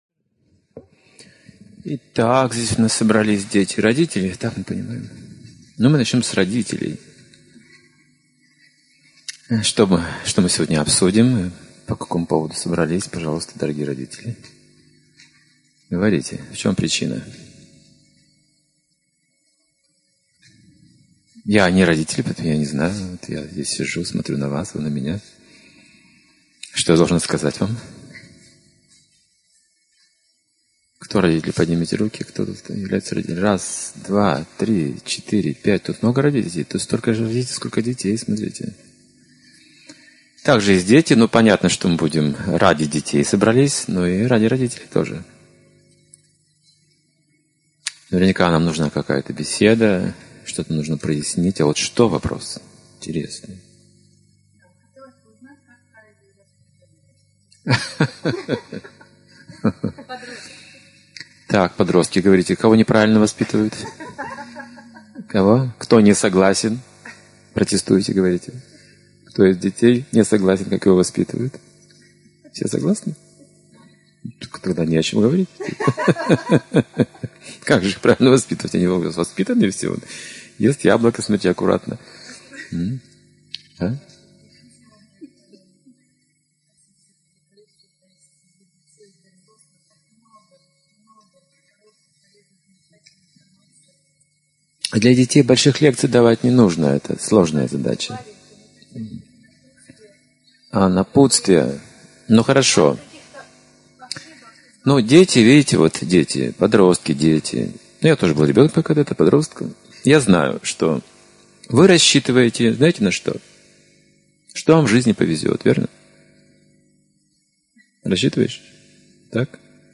Фестиваль Святоустье. Встреча с детьми и родителями (2016, Рига)